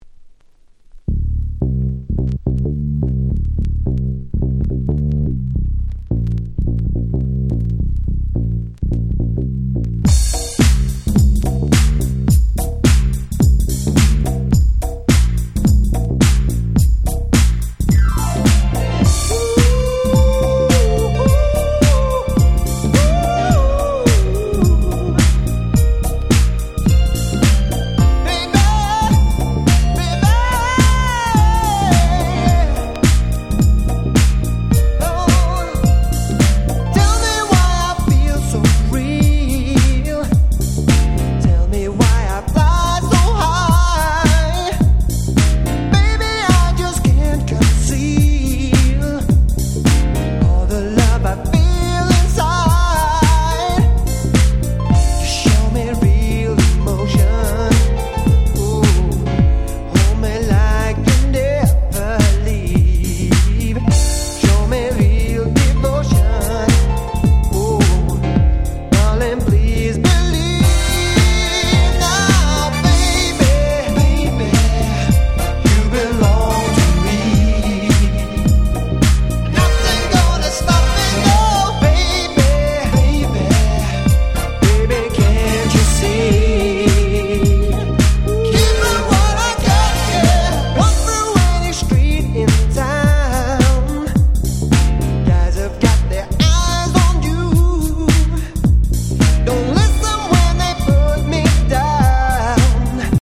92' Super Nice UK R&B / UK Street Soul !!
90's R&B